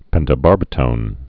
(pĕntə-bärbĭ-tōn)